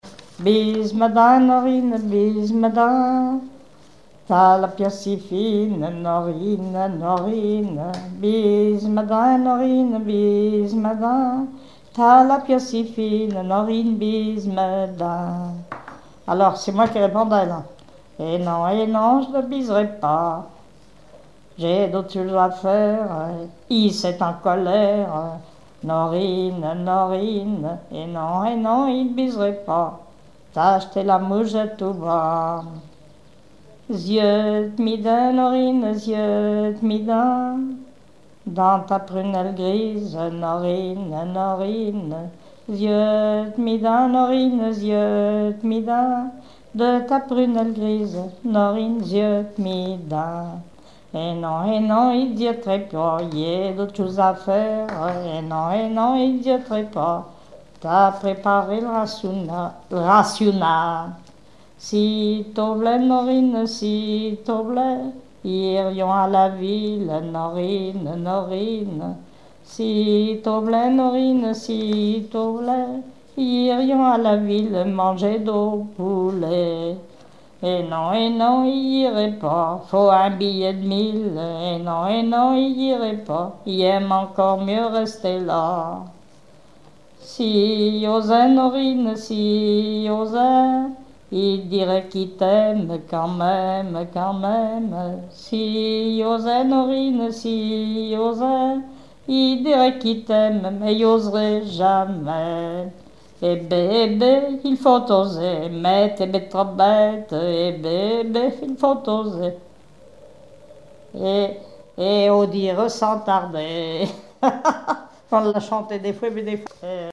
Chansons locales et traditionnelles
Pièce musicale inédite